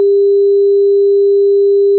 A two-pole Butterworth filter is designed using the method illustrated in Example 12.3. The cutoff frequency is 1000 hz and the sampling period is T=1/10000.